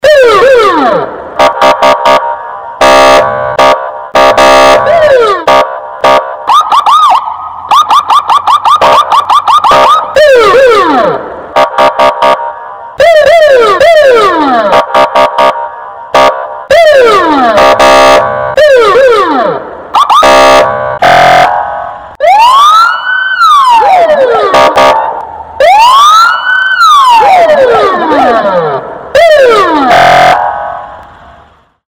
На этой странице собраны детские звуки полиции: сирены, переговоры по рации, сигналы машин.
Звук сигнала автополиции ГИБДД